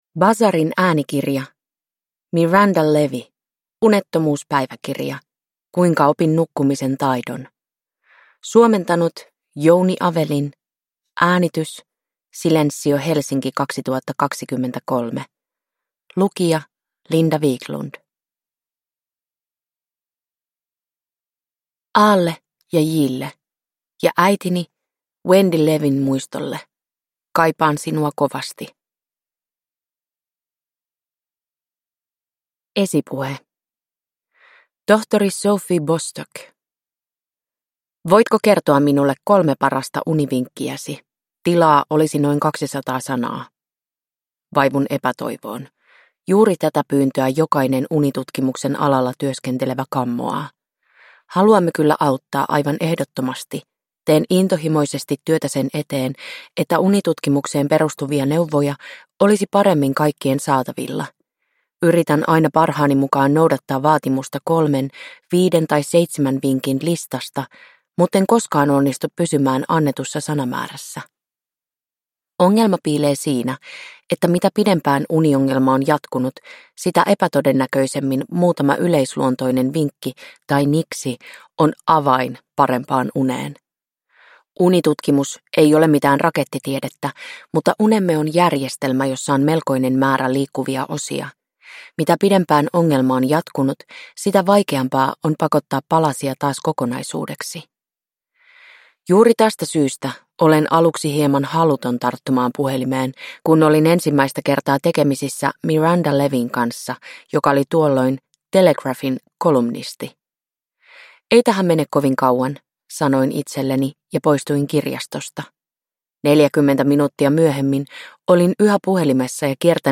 Unettomuuspäiväkirja - Kuinka opin nukkumisen taidon – Ljudbok – Laddas ner